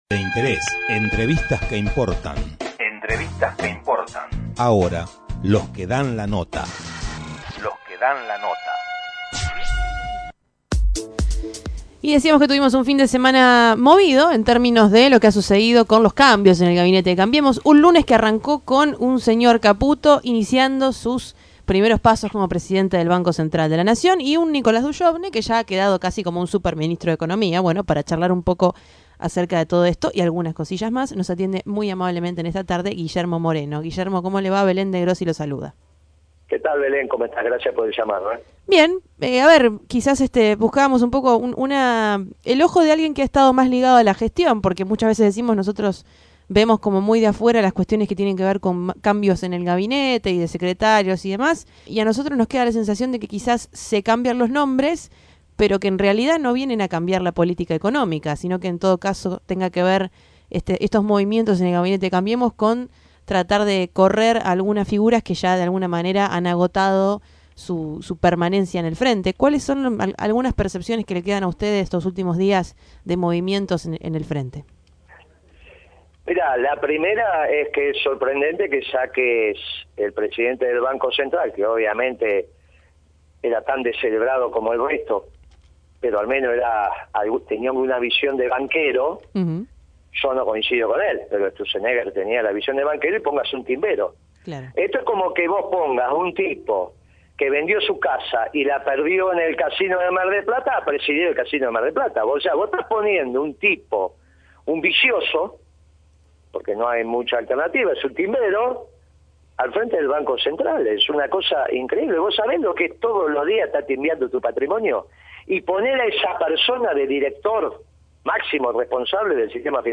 Escuchá la entrevista completa a Guillermo Moreno:
Guillermo Moreno, en comunicación con Tren Urbano.